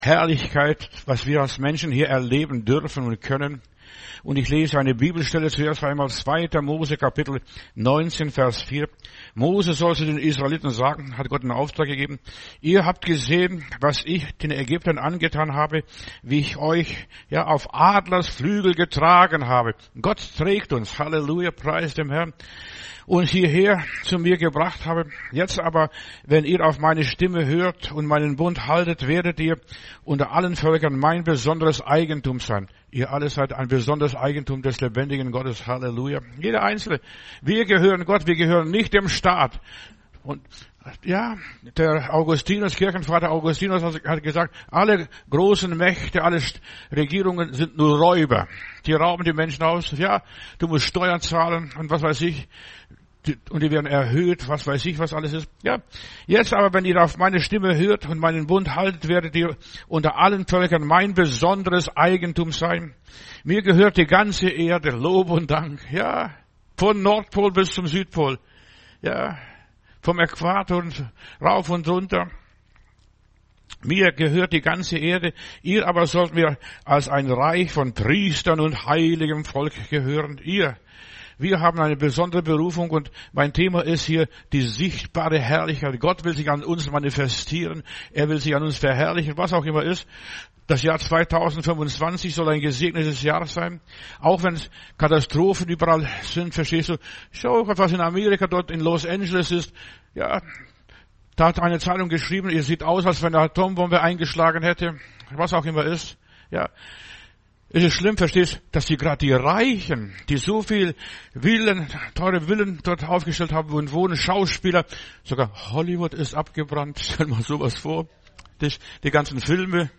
Predigt herunterladen: Audio 2025-01-12 Die sichtbare Herrlichkeit Video Die sichtbare Herrlichkeit